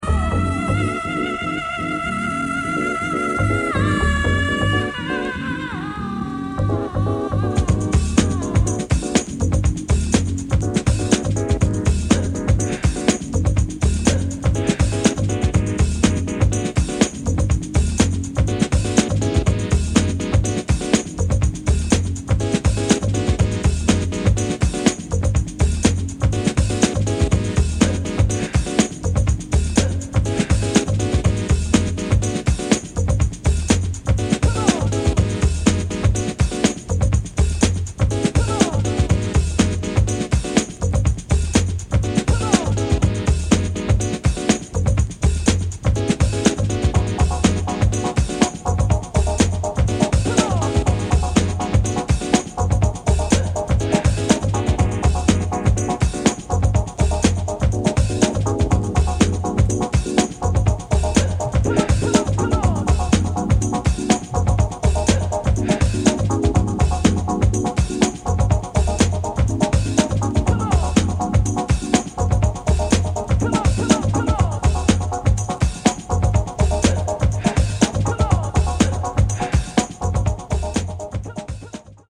UK House holy grailer. Classic cut&paste House